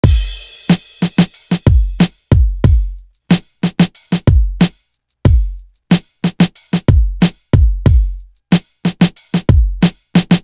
Shootem Up Drum.wav